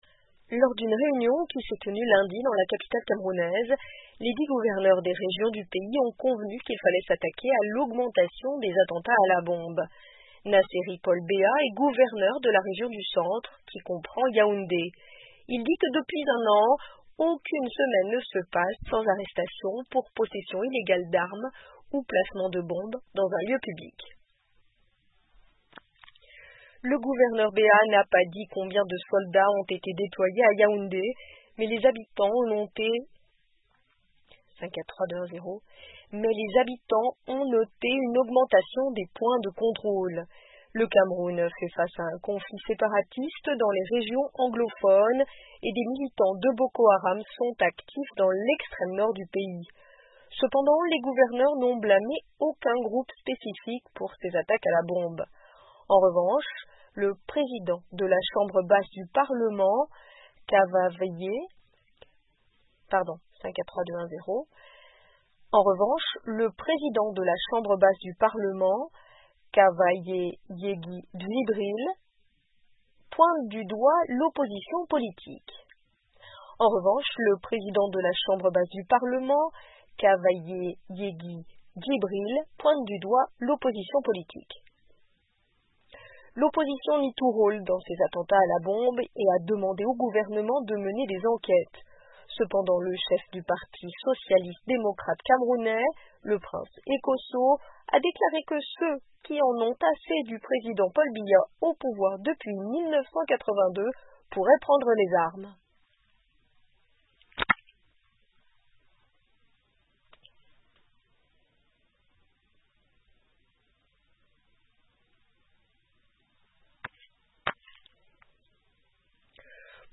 Les autorités camerounaises ont déployé des troupes dans la capitale Yaoundé pour arrêter des hommes armés qui, selon elles, tentent de déstabiliser le gouvernement. Plusieurs bombes ont explosé à Yaoundé au cours des deux derniers mois, blessant au moins 22 personnes. Un Reportage